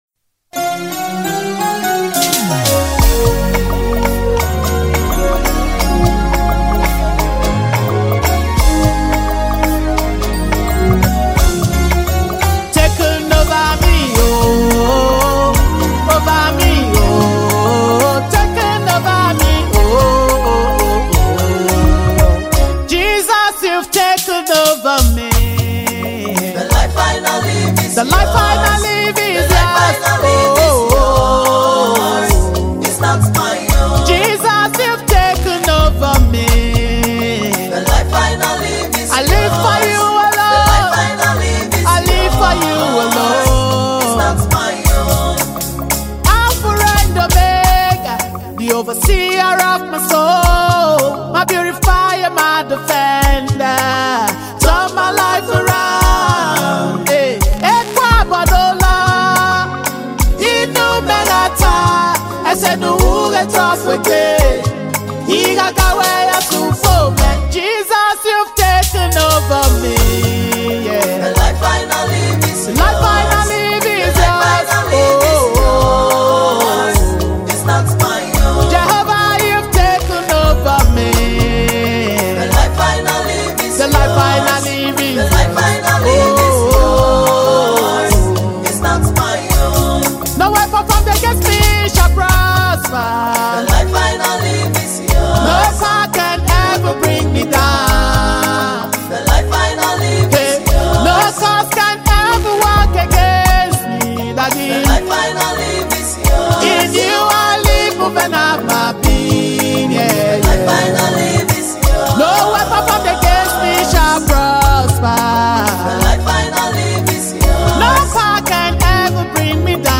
UK based Gospel singer
a powerful soul lifting single